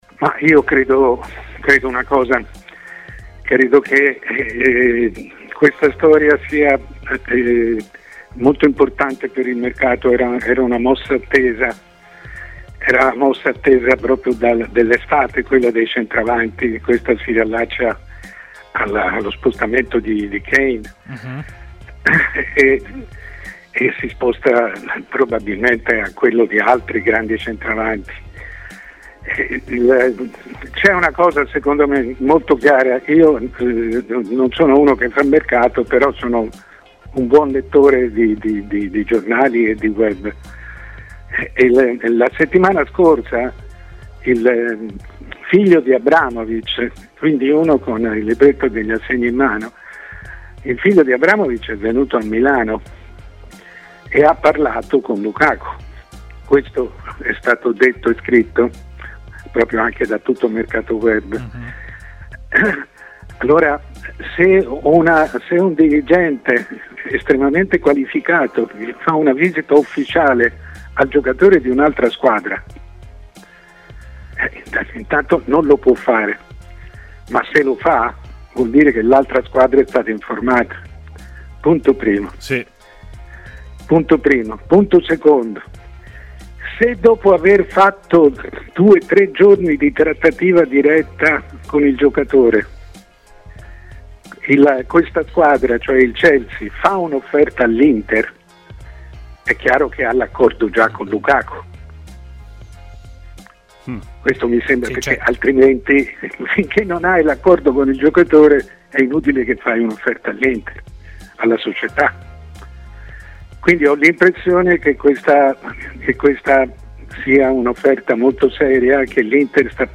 Mario Sconcerti, decano del giornalismo sportivo e opinionista di TMW Radio, è intervenuto in diretta a Stadio Aperto, iniziando dall'interesse del Chelsea su Lukaku: "Io credo che questa storia sia molto importante per il mercato.